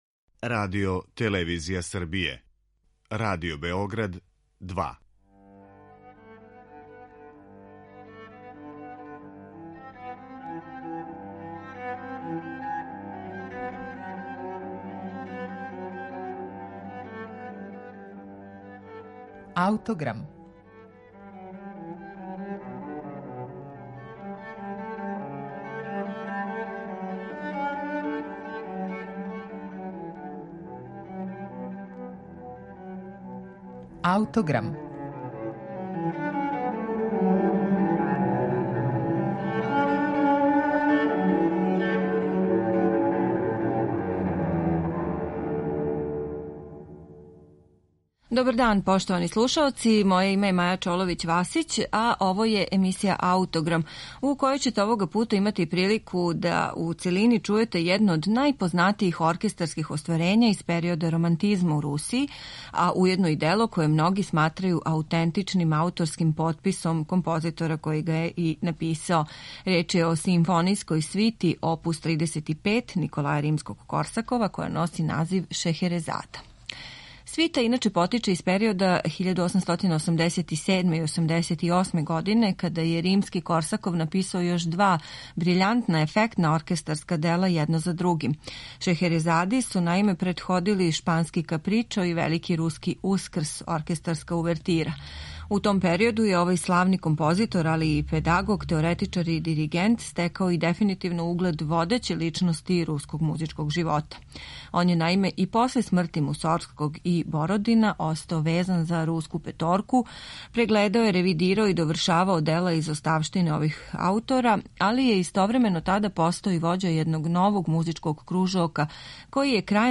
Реч је о симфонијској свити Шехеразада оп. 35 Николаја Римског-Корсакова из 1888. године.